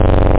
tb303-samples-1
1 channel
303-ravehit.mp3